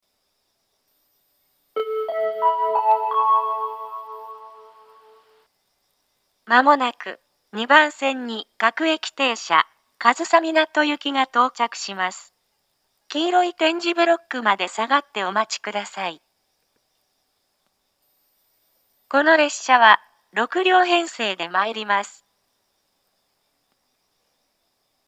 ２番線接近放送
自動放送は合成音声でした。